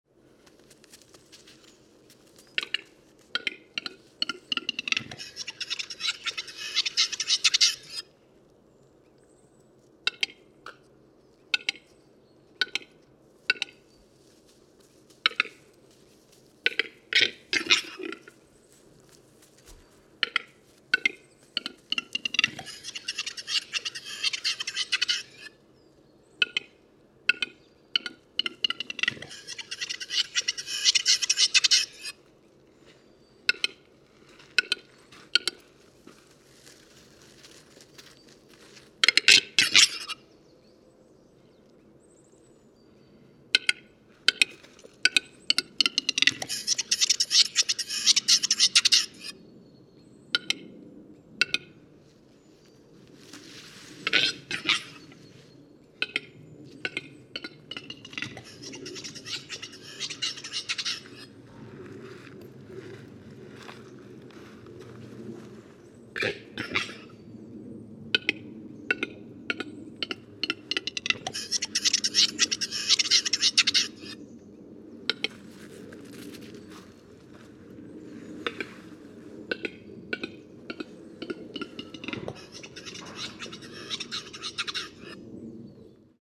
Red List Twenty Nine – Capercaillie
But this wonderful article in The Guardian, which features a number of recordings of endangered animals, says that the call of the capercaillie sounds like  ‘the sound of clipping horses hooves, followed by a bottle opening and squirting water and ending with knife sharpening.’